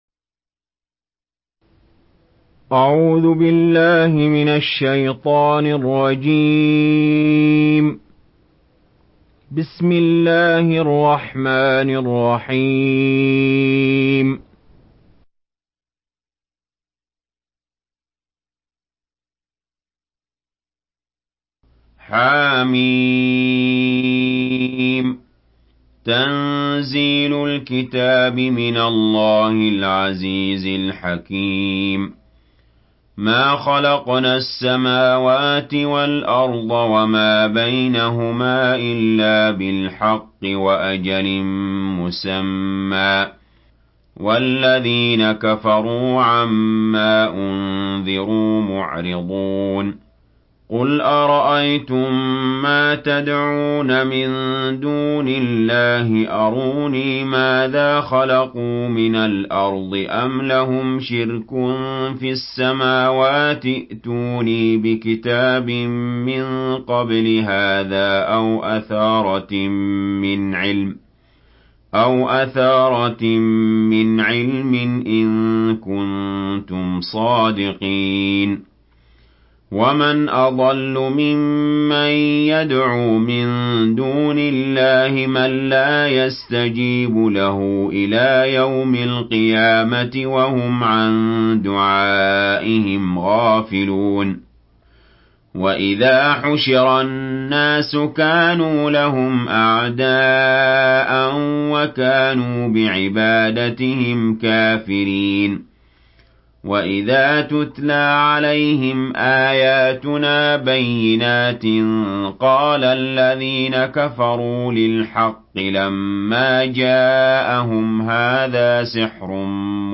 Surah আল-আহক্বাফ MP3 by Ali Jaber in Hafs An Asim narration.